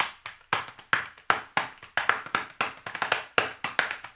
HAMBONE 18-R.wav